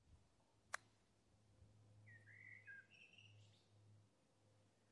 体育 " HSN高尔夫芯片
描述：用削片机打高尔夫球。